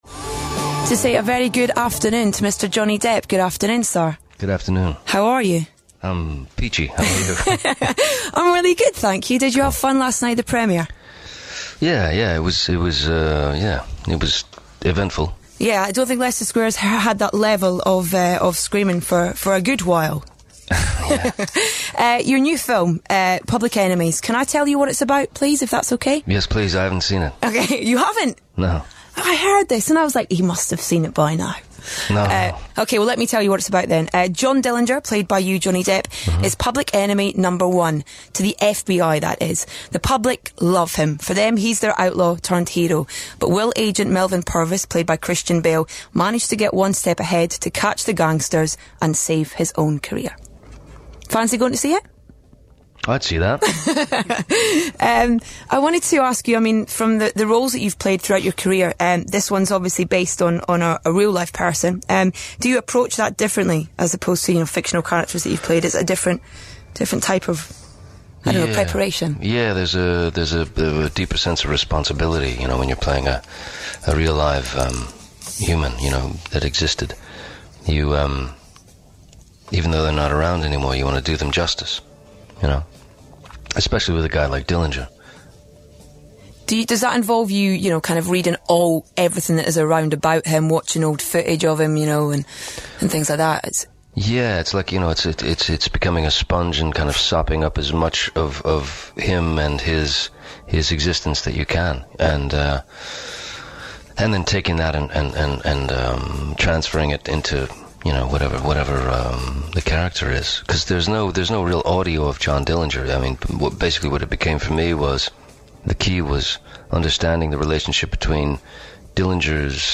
Public Enemies Radio Interview
Public Enemies Radio Interview: BBC By Edith Bowman